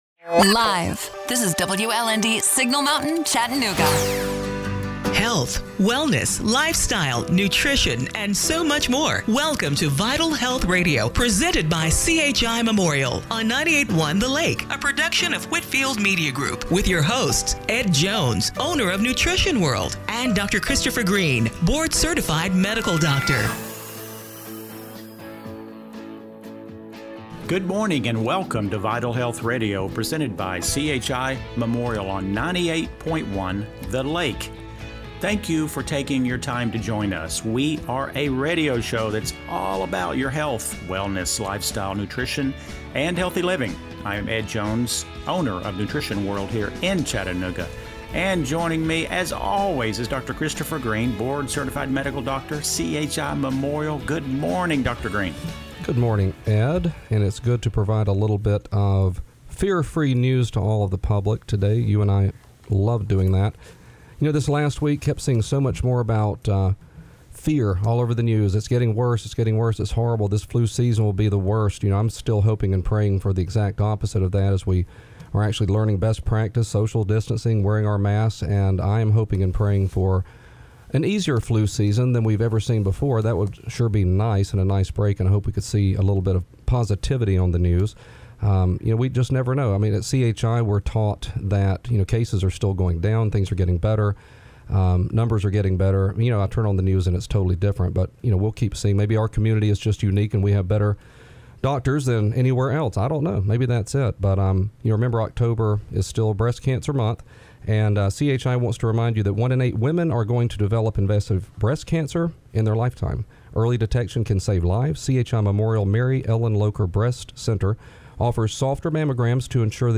October 11, 2020 – Radio Show - Vital Health Radio